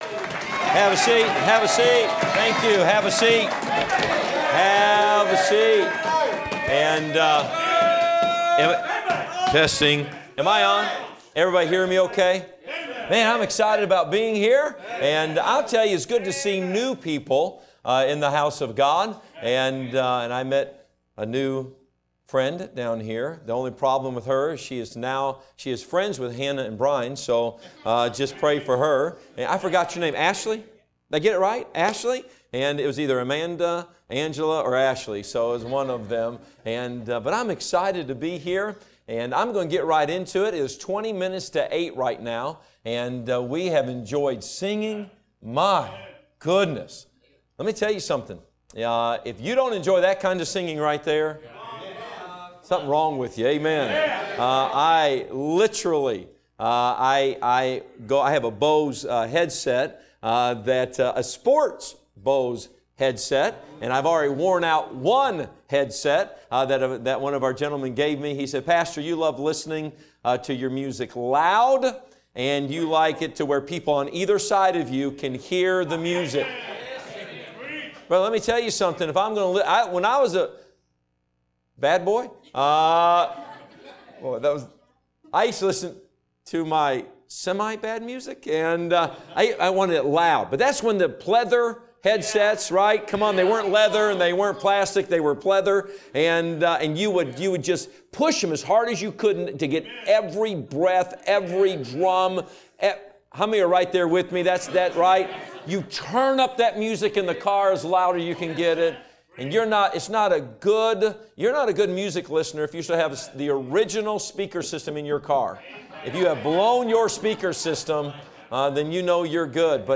Sermons preached from the pulpit of Anchor Baptist Church in Columbus, Ohio.